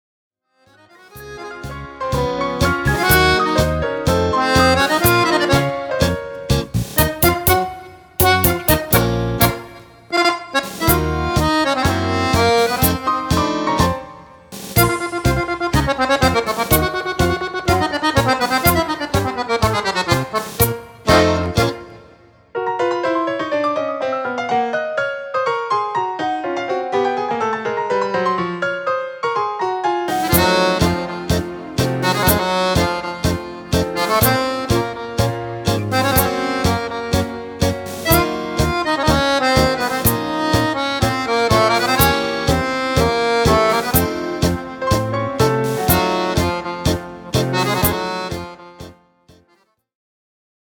Tango
Fisarmonica